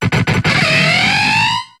Cri de Yanmega dans Pokémon HOME.